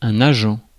Ääntäminen
France: IPA: [a.ʒɑ̃]